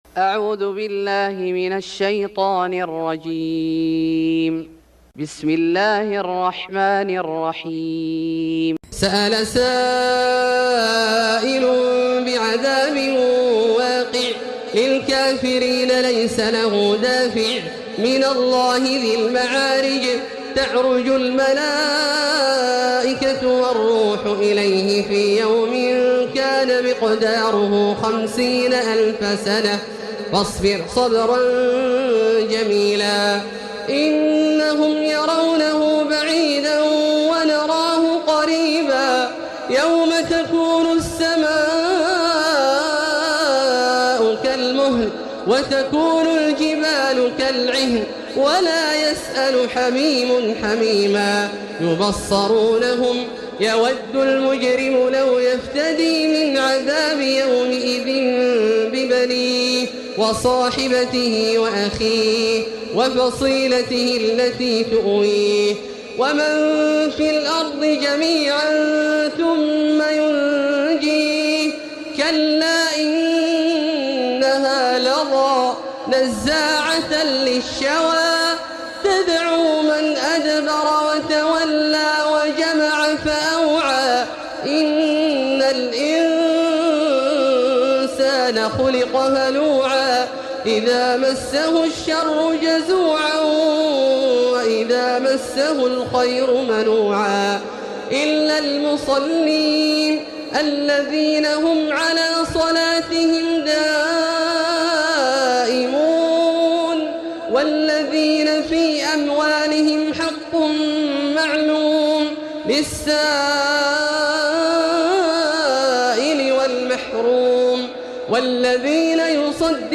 سورة المعارج Surat Al-Maarij > مصحف الشيخ عبدالله الجهني من الحرم المكي > المصحف - تلاوات الحرمين